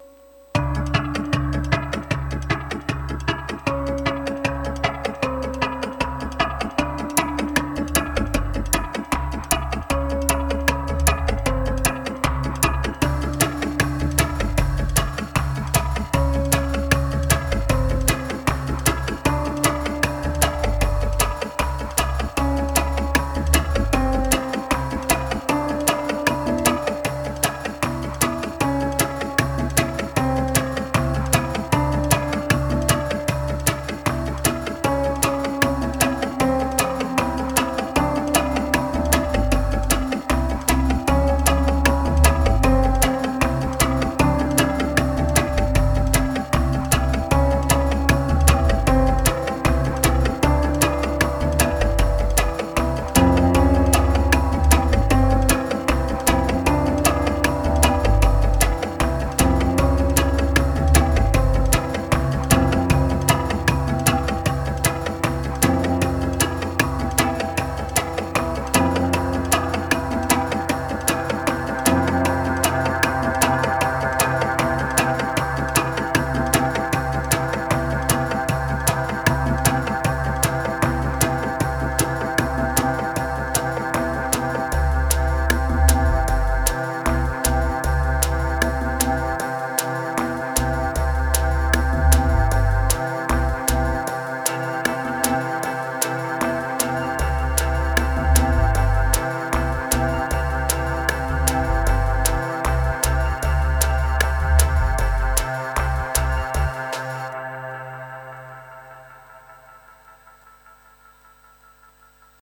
524📈 - 63%🤔 - 77BPM🔊 - 2021-11-23📅 - 169🌟